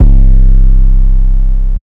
Young Metro [808].wav